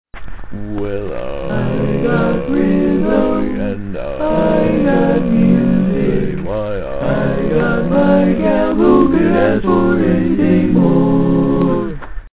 Key written in: D♭ Major
How many parts: 5
Type: Other male
All Parts mix: